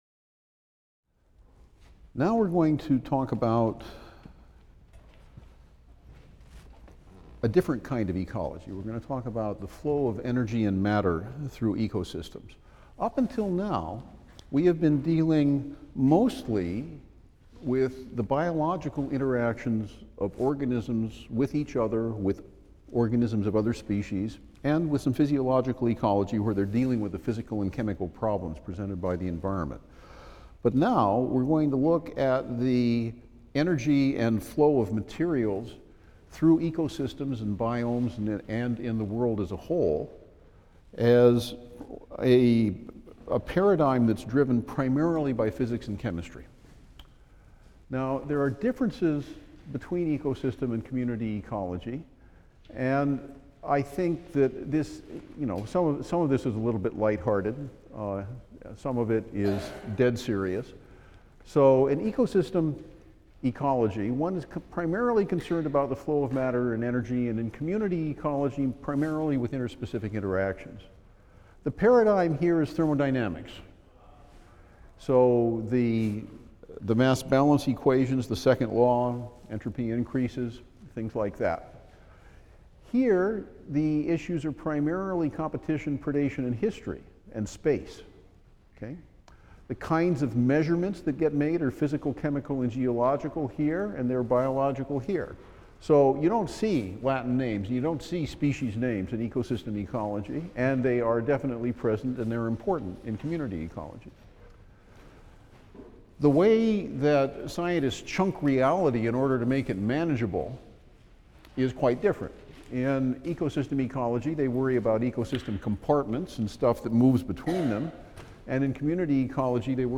E&EB 122 - Lecture 30 - Energy and Matter in Ecosystems | Open Yale Courses